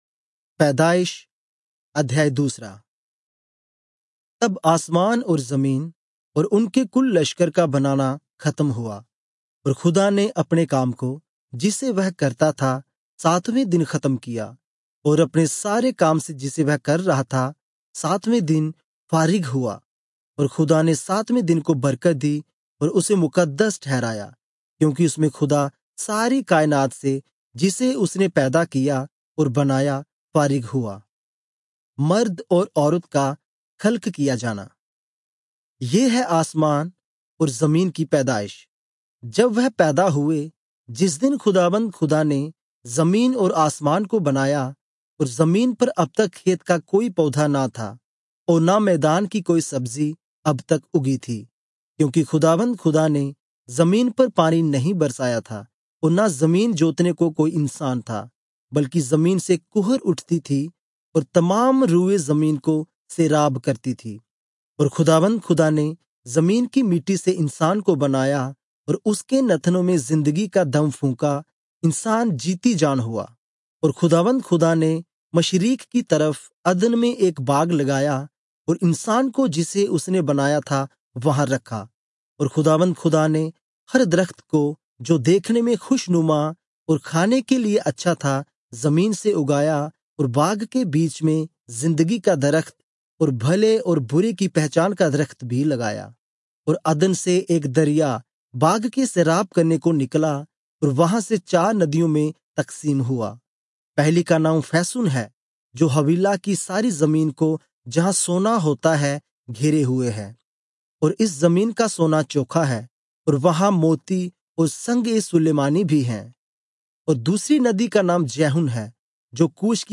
Urdu Audio Bible - Genesis 45 in Irvur bible version